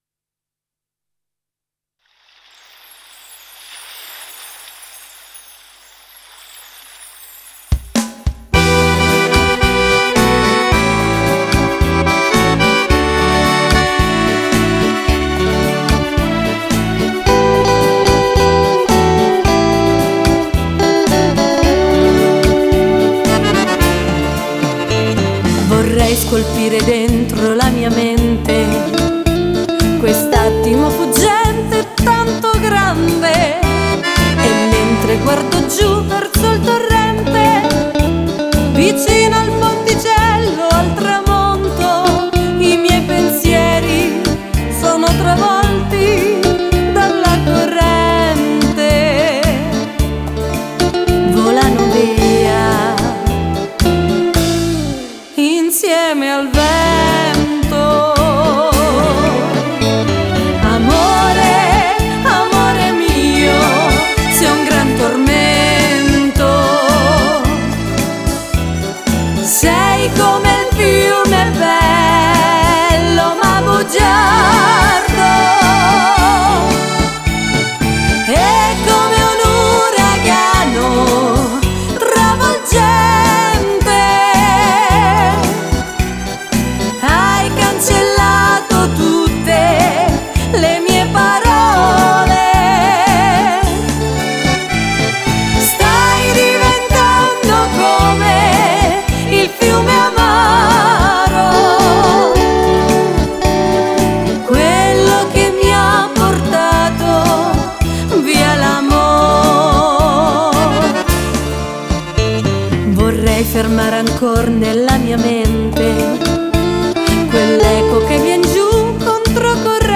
Orchestra Spettacolo
Beguine
Canzone struggente e orecchiabile